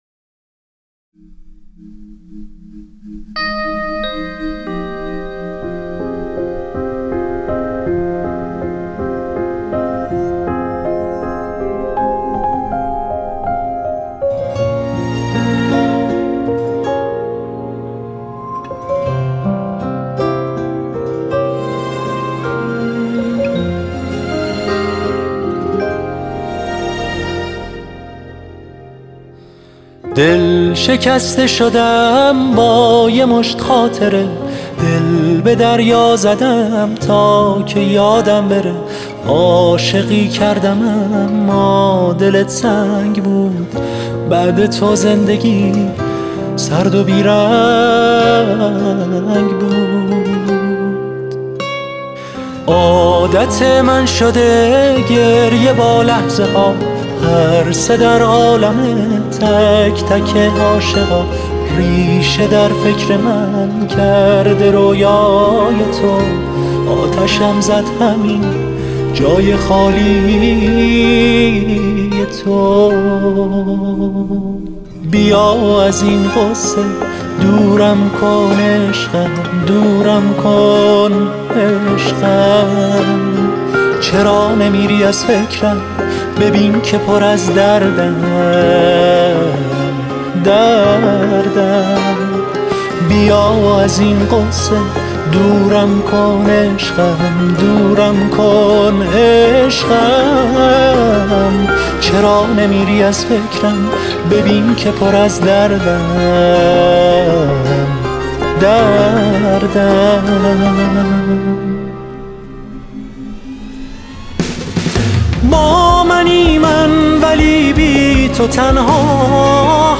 ویولن
گیتار